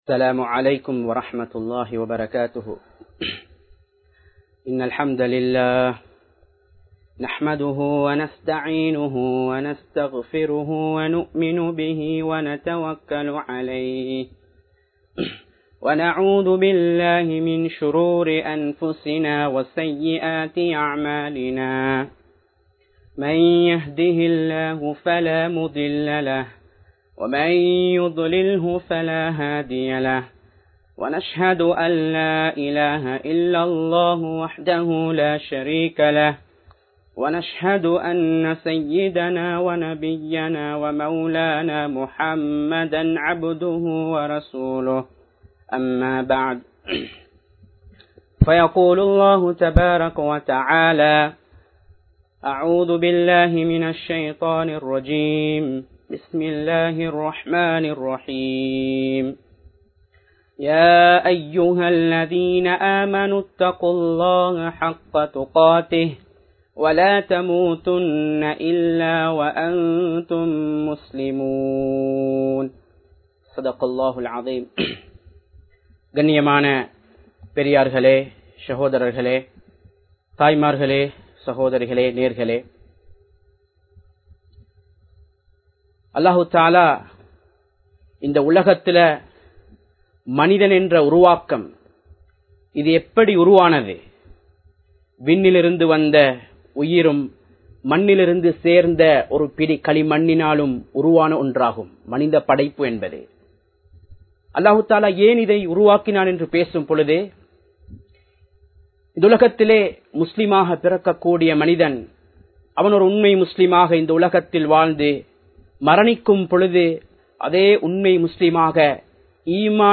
Maranaththai Maranthu Vidathe Manitha (மரணத்தை மறந்து விடாதே மனிதா) | Audio Bayans | All Ceylon Muslim Youth Community | Addalaichenai
Live Stream